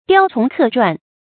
雕蟲刻篆 注音： ㄉㄧㄠ ㄔㄨㄙˊ ㄎㄜˋ ㄓㄨㄢˋ 讀音讀法： 意思解釋： 見「雕蟲篆刻」。